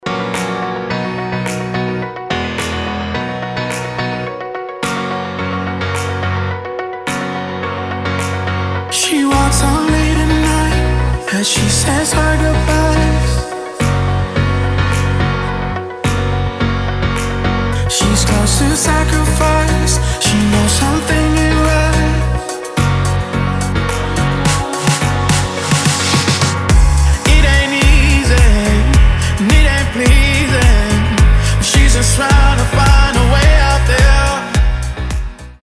• Качество: 256, Stereo
мужской вокал
dance
Electronic
club
vocal